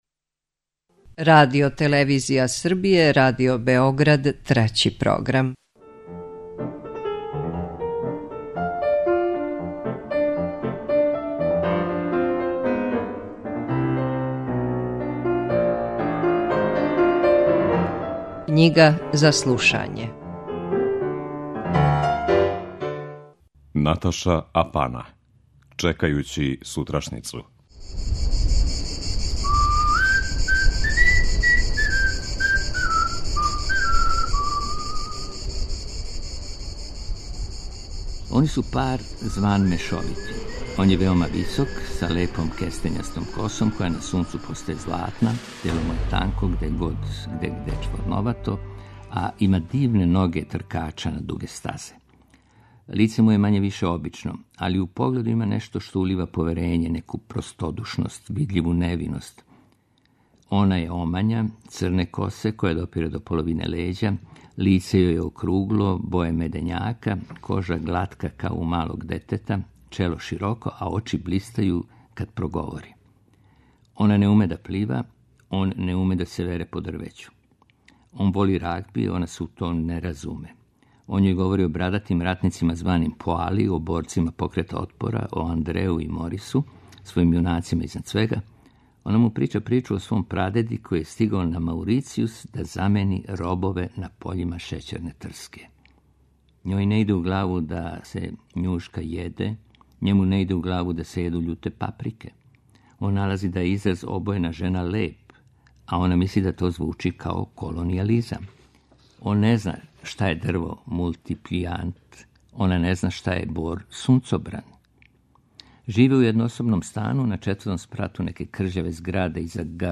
Књига за слушање: Наташа Апана – Чекајући сутрашњицу (3)